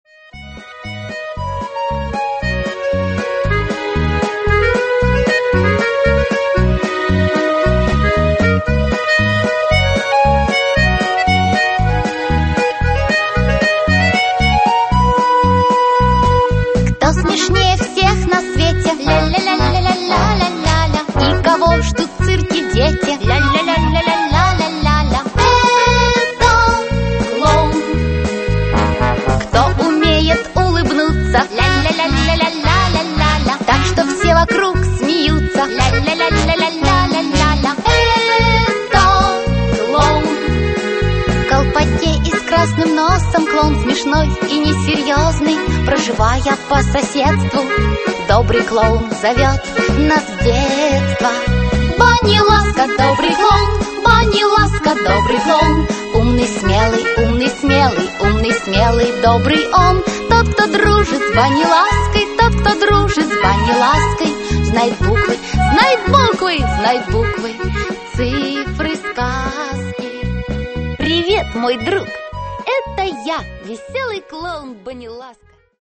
Аудиокнига Веселые уроки Баниласки. Времена года, месяцы, природа, животные, растения | Библиотека аудиокниг